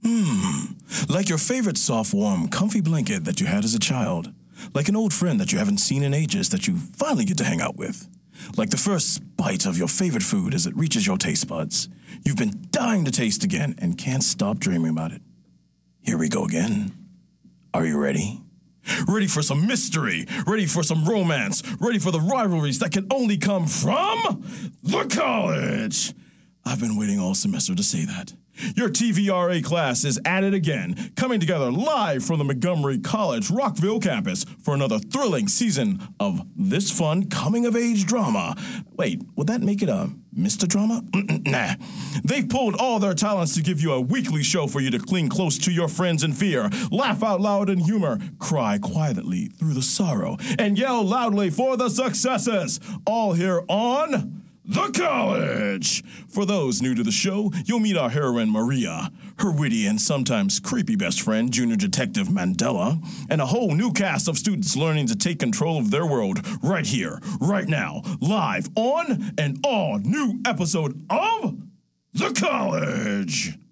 Voice Overs and Demos